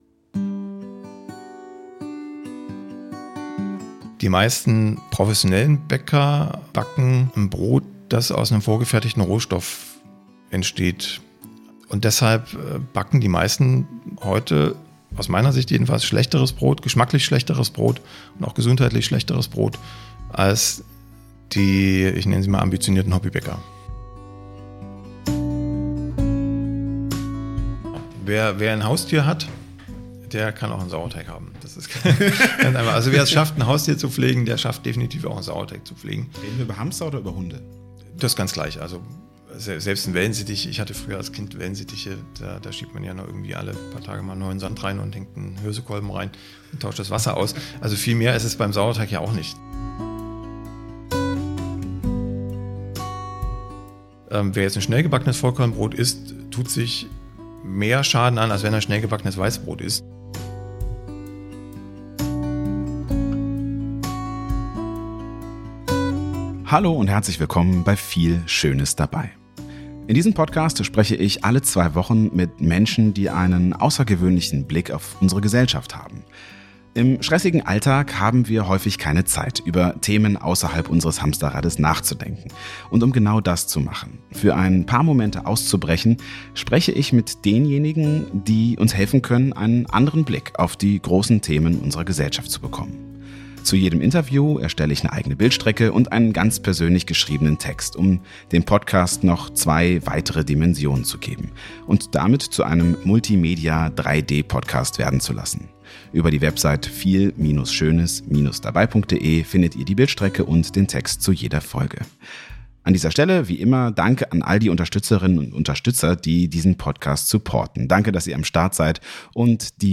Mit ihm spreche ich über das Brotbacken und die Zukunft des Brotes.